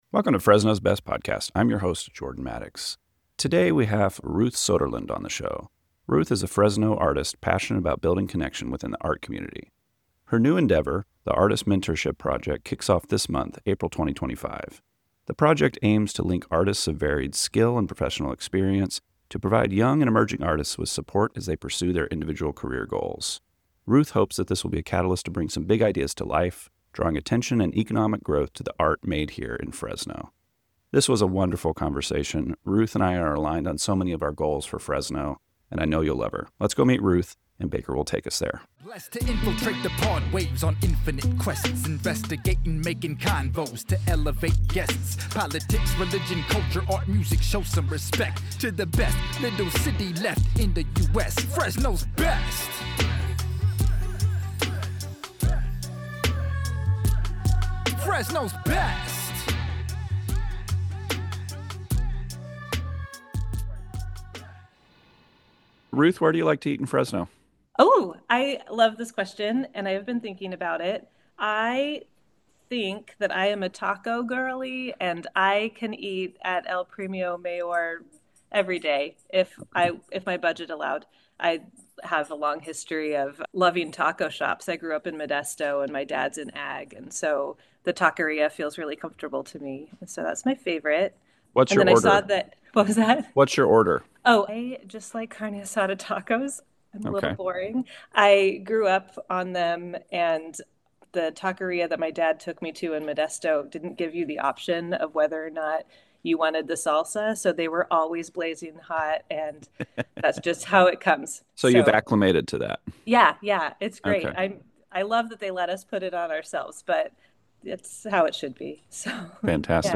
This was a wonderful conversation.